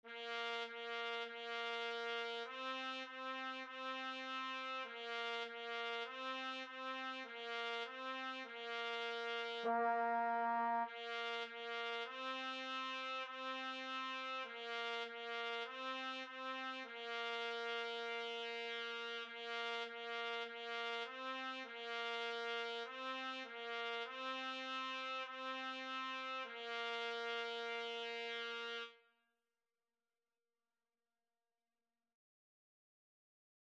4/4 (View more 4/4 Music)
Bb4-C5
Beginners Level: Recommended for Beginners
Trumpet  (View more Beginners Trumpet Music)
Classical (View more Classical Trumpet Music)